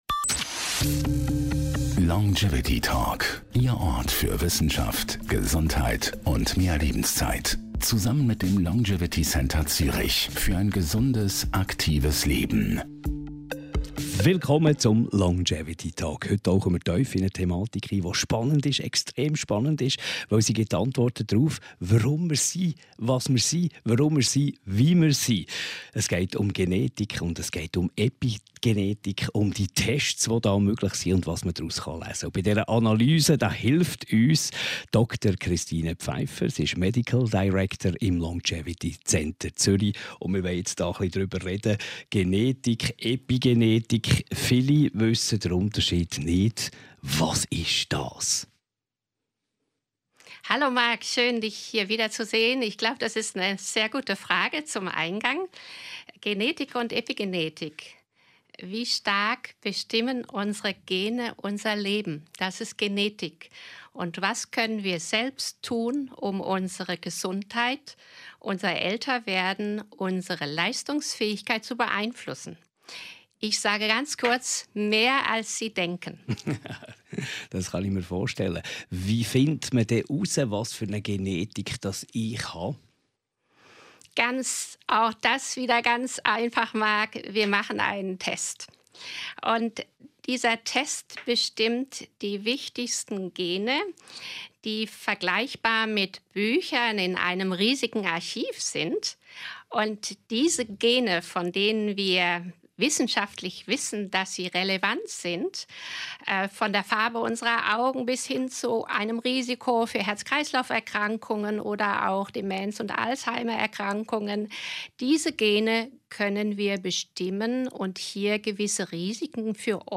Longevity Talk Podcast